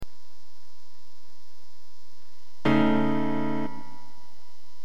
Using a Java Media Framework MIDI application that processes the Voynich text, aach character is mapped to a note on the MIDI scale in a two octave range: Note = 48 + i mod 24
Each word is played as one or more chords of these notes, using the Grand Piano instrument.